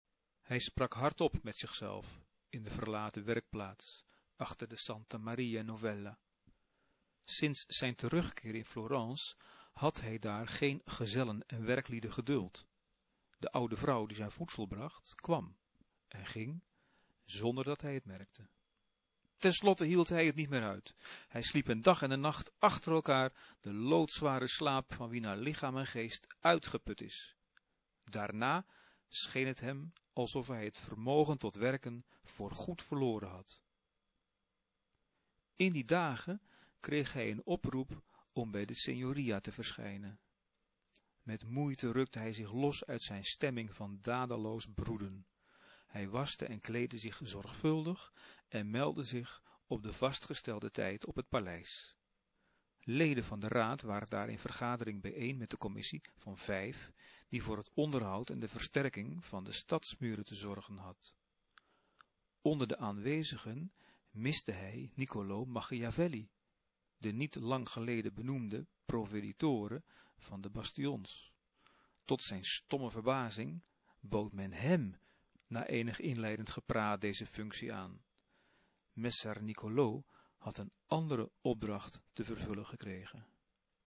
Betekent: het fragment wordt voorgelezen. (MP-3)